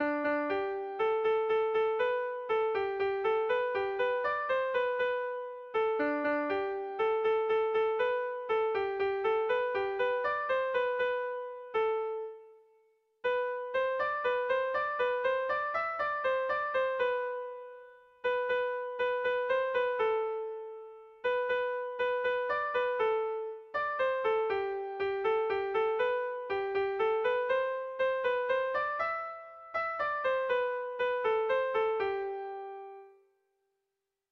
Erlijiozkoa
Zortziko ertaina (hg) / Lau puntuko ertaina (ip)
AABD